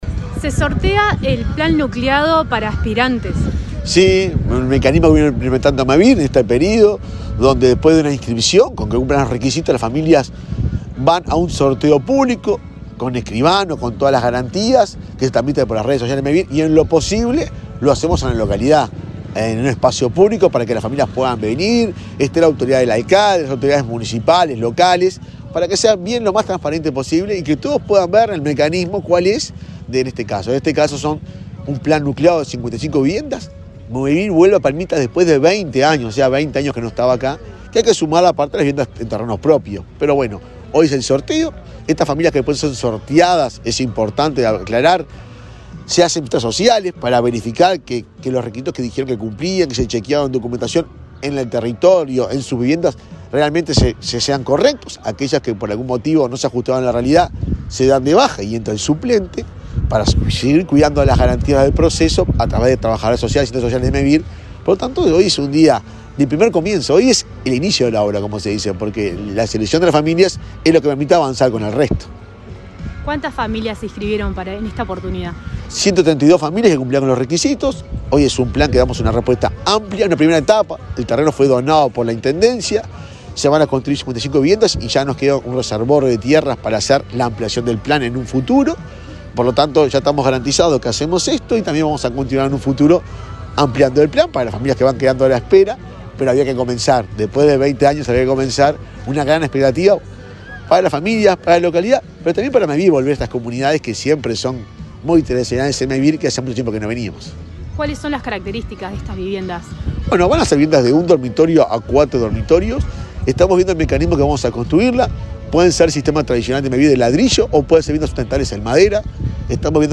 Entrevista al presidente de Mevir, Juan Pablo Delgado
El presidente de Mevir, Juan Pablo Delgado, dialogó con Comunicación Presidencial en Soriano, donde participó en el sorteo de un plan de 55 viviendas